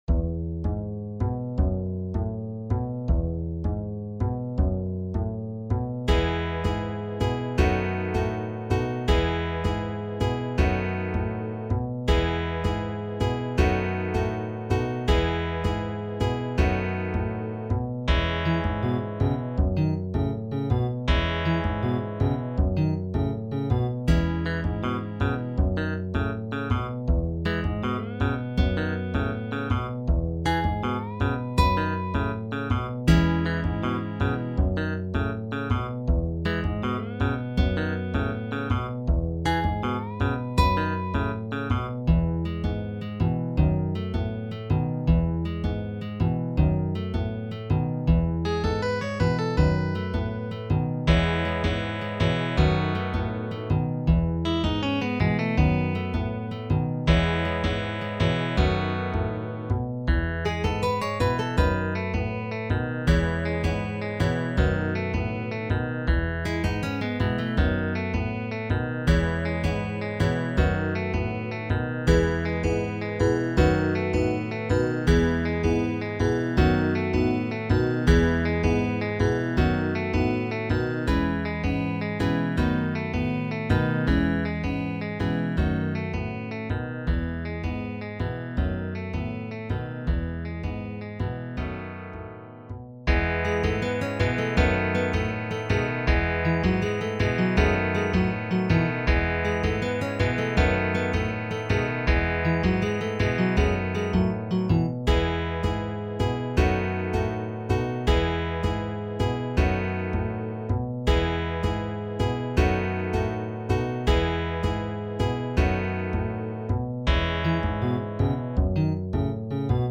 Arrangement für 4 Gitarren